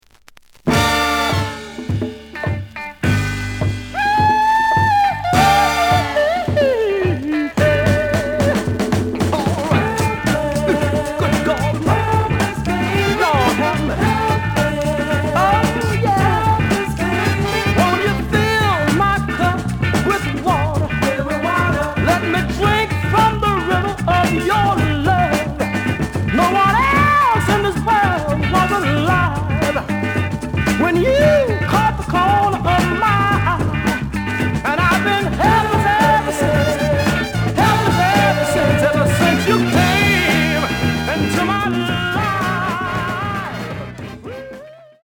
The audio sample is recorded from the actual item.
●Genre: Soul, 60's Soul
Looks good, but slight noise on both sides.)